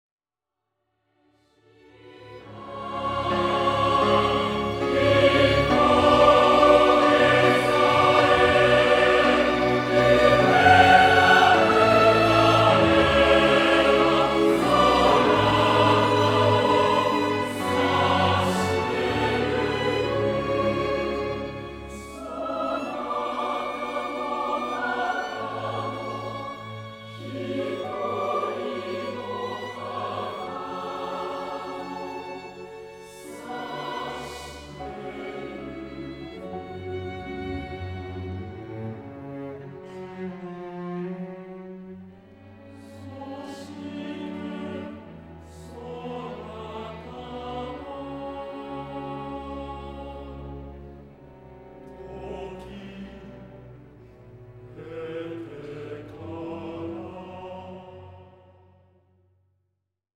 弦楽とピアノ伴奏版